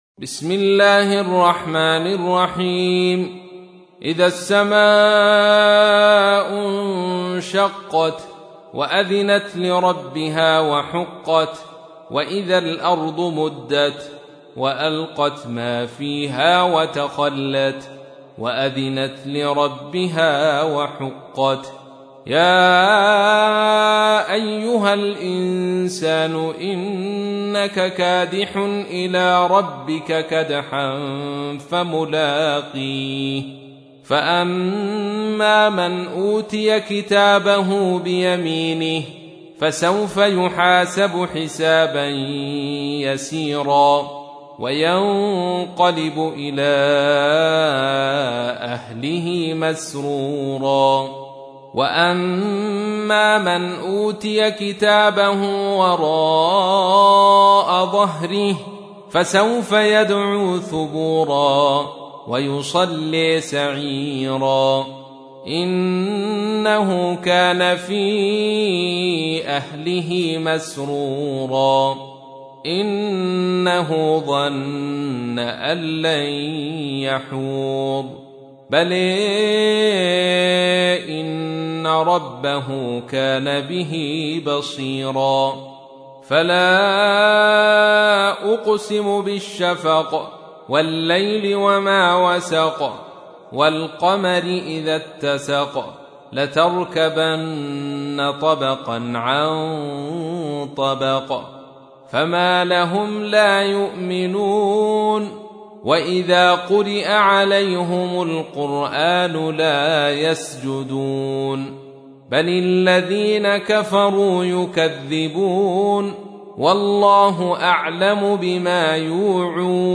تحميل : 84. سورة الانشقاق / القارئ عبد الرشيد صوفي / القرآن الكريم / موقع يا حسين